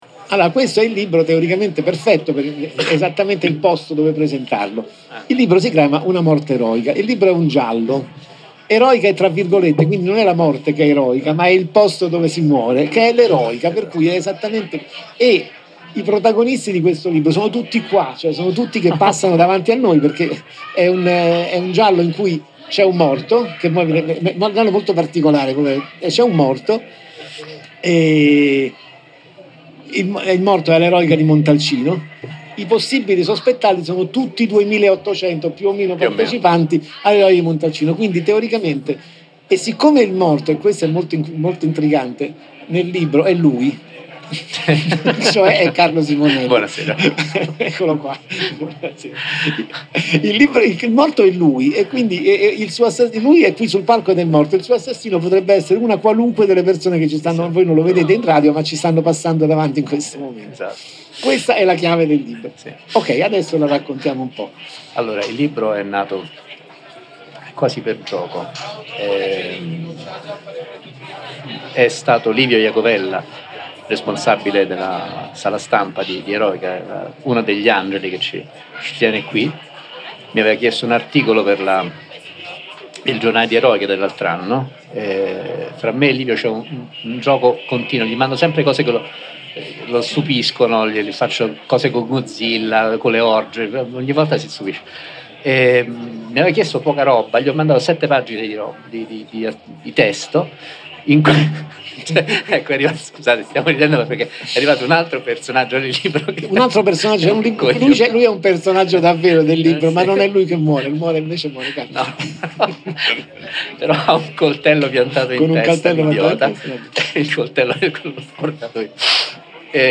intervista-radio-gaiole.mp3